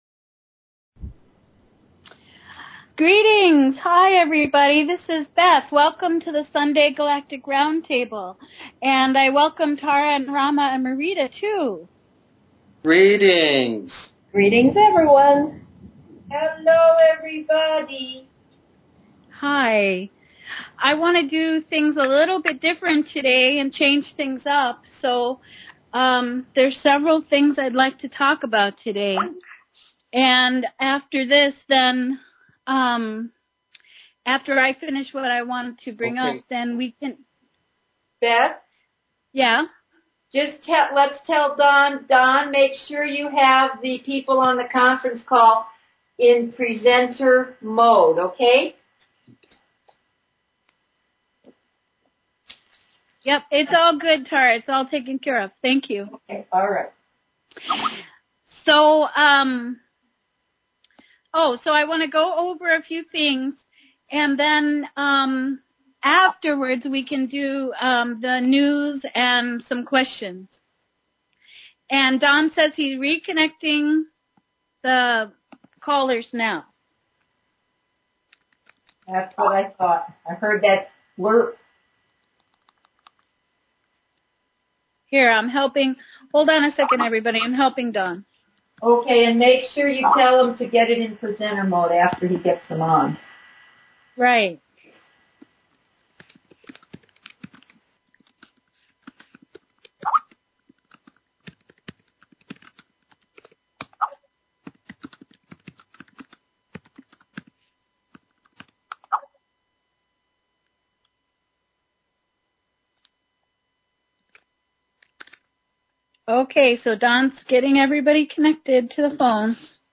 Talk Show Episode, Audio Podcast, The_Galactic_Round_Table and Courtesy of BBS Radio on , show guests , about , categorized as